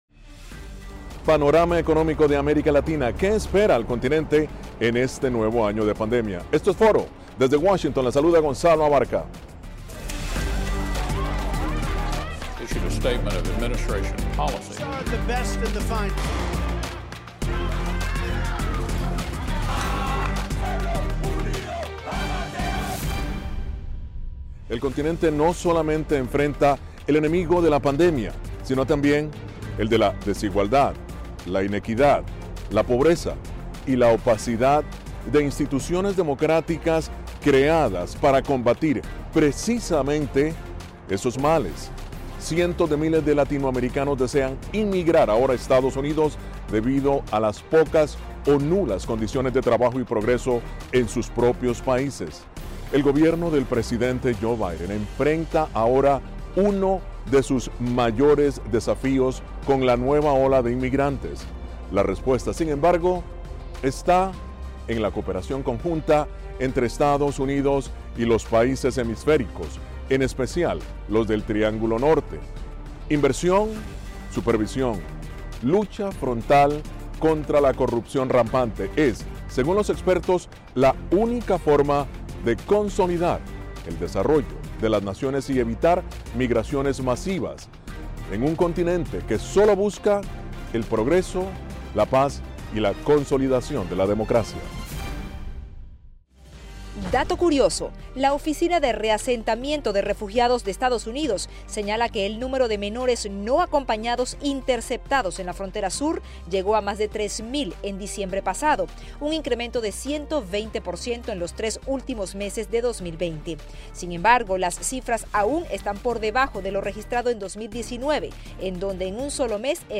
Foro: Perspectivas económicas 2021